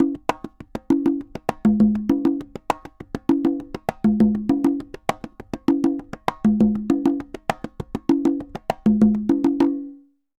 Congas_Salsa 100_6.wav